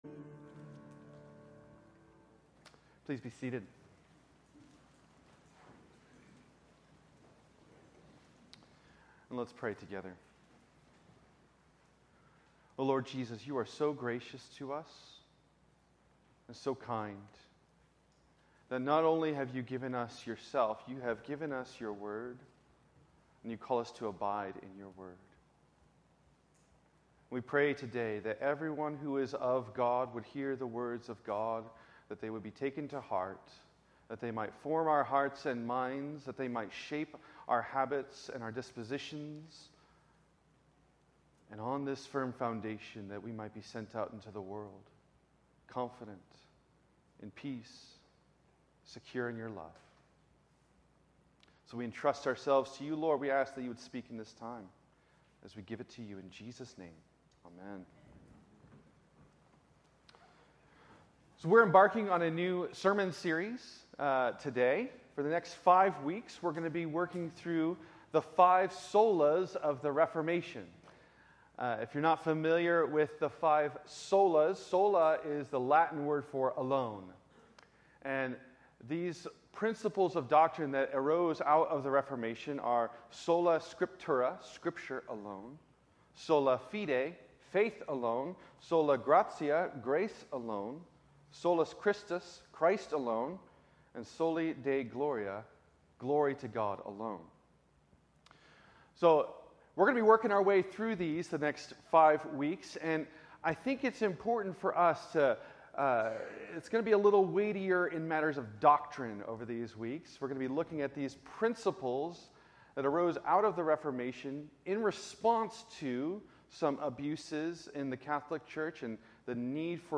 In this first message of this sermon series on the Five Solas of the Reformation